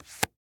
card_flips.ogg